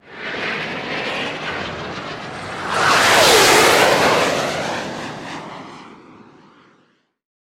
Missile.wav